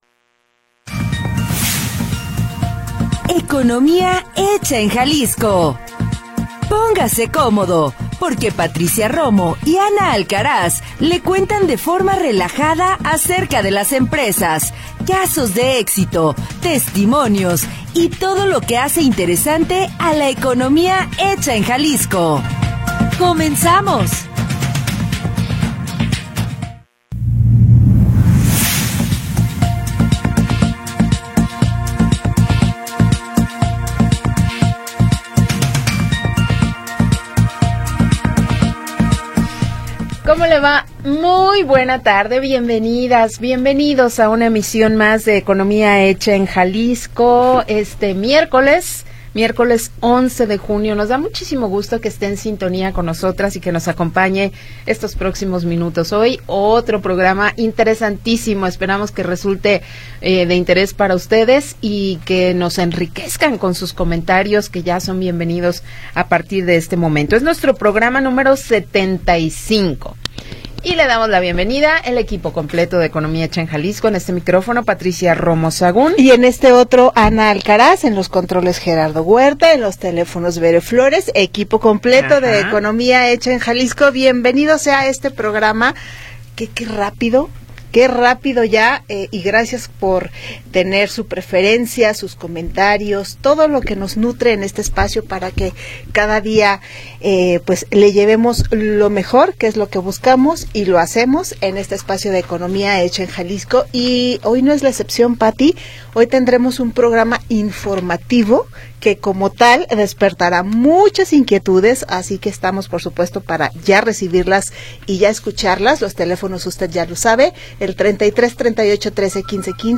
de forma relajada
Programa transmitido el 11 de Junio de 2025.